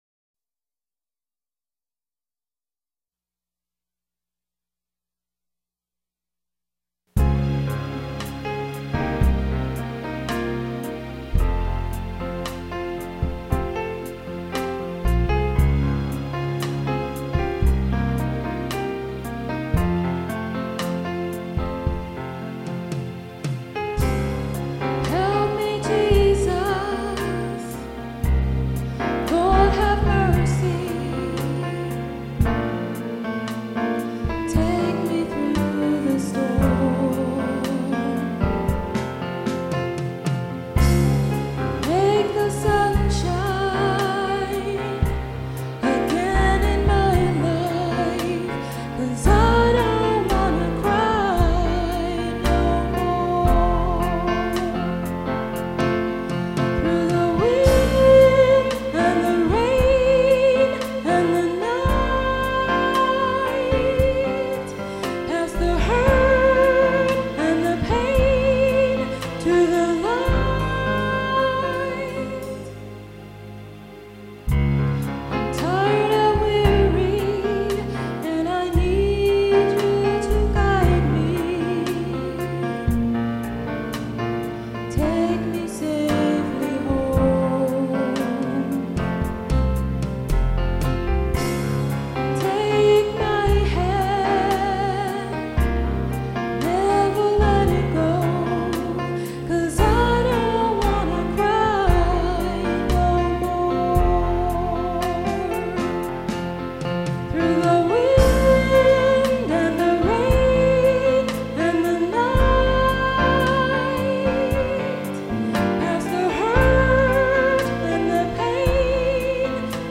vocal version